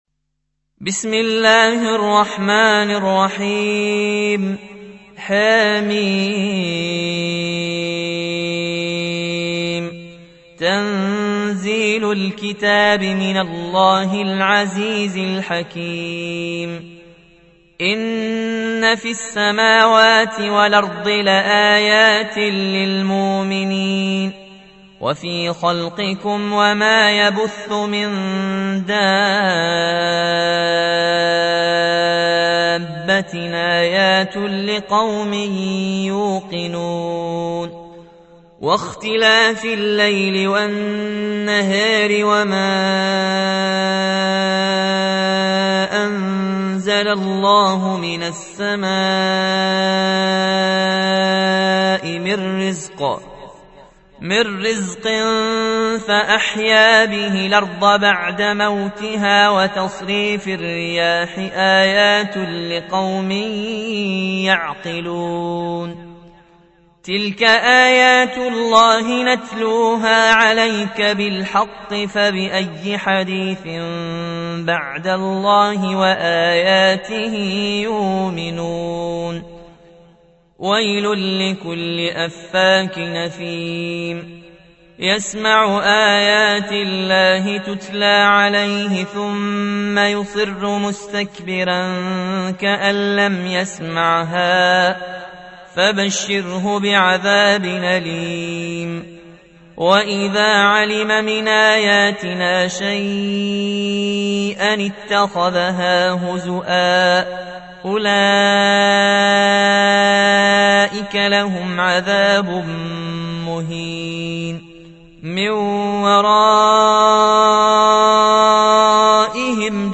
45. سورة الجاثية / القارئ